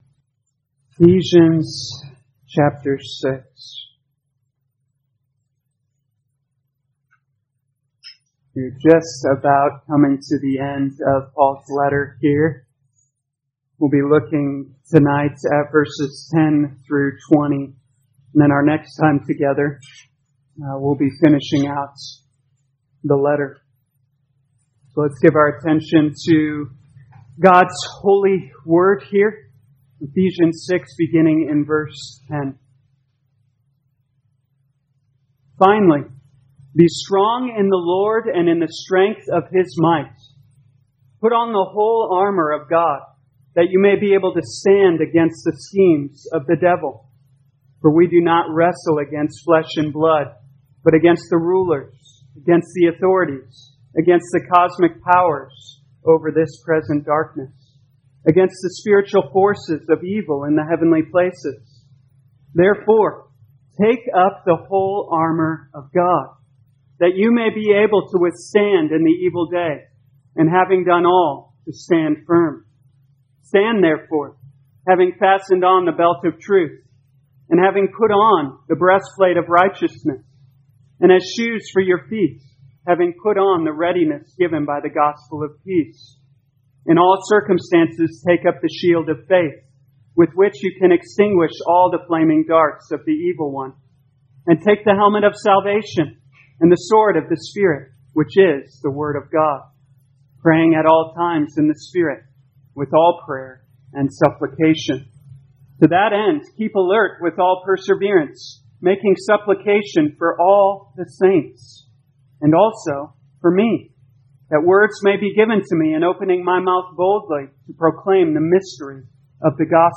2021 Ephesians Evening Service Download